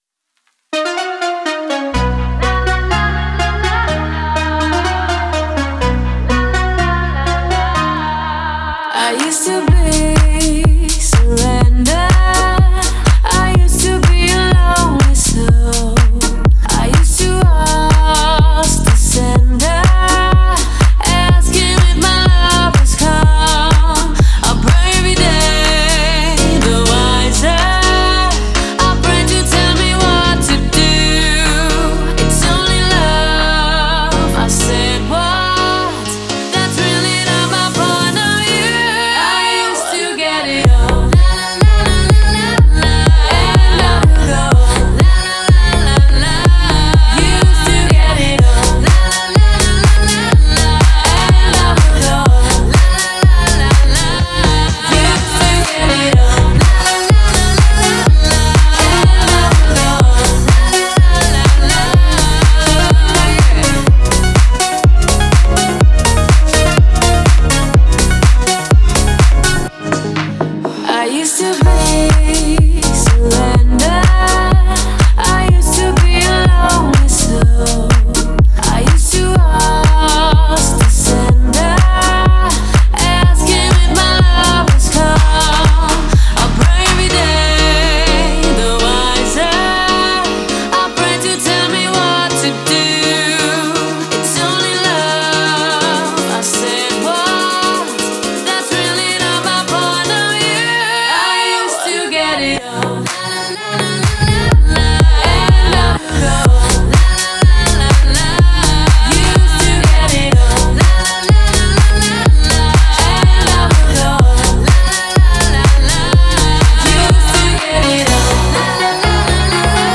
это яркий трек в жанре EDM